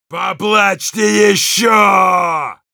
Sounds Yell Rus
Heavy_yell5_ru.wav